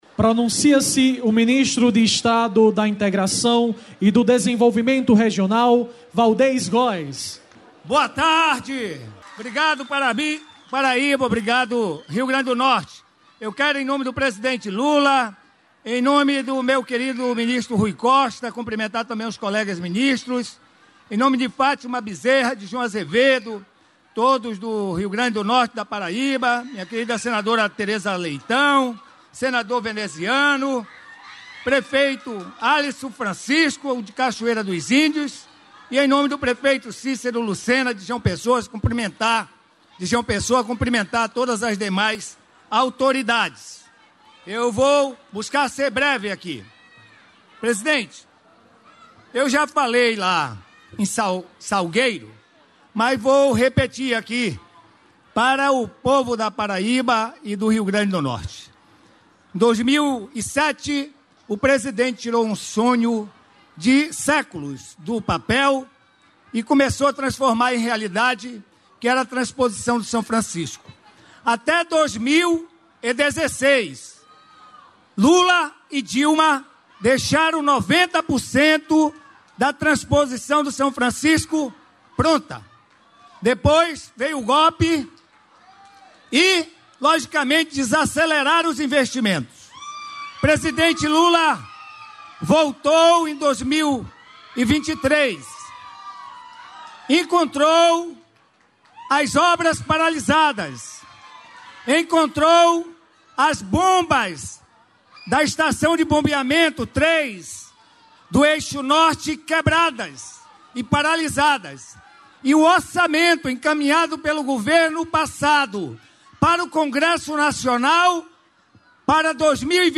Íntegra do discurso dos ministros Waldez Góes, da Integração e Desenvolvimento Regional, e Rui Costa, da Casa Civil, na cerimônia de entrega do Marco 1 do Ramal do Apodi, em Cachoeira dos Índios (PB), nesta quarta-feira (28).